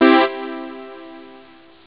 reverb1.st.wav